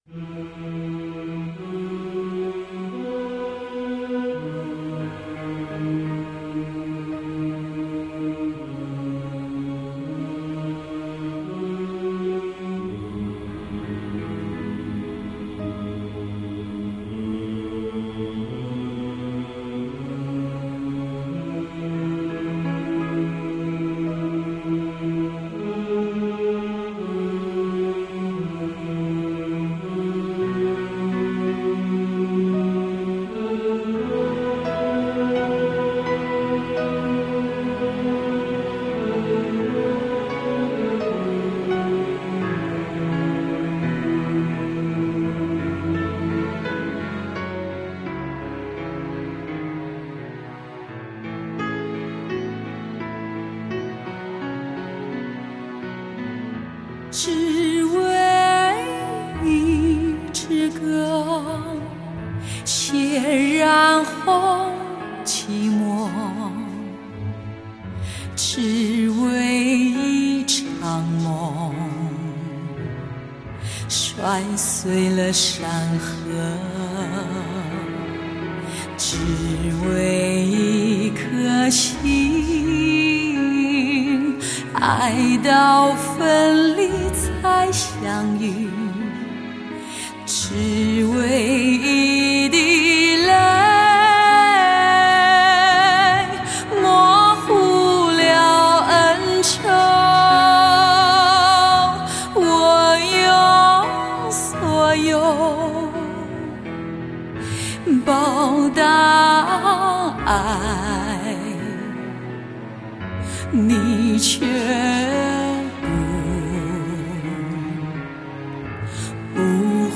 充满质感的磁性嗓音